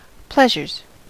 Ääntäminen
Ääntäminen US : IPA : [ˈplɛʒ.ɚz] Tuntematon aksentti: IPA : /ˈplɛʒ.əz/ Haettu sana löytyi näillä lähdekielillä: englanti Pleasures on sanan pleasure monikko.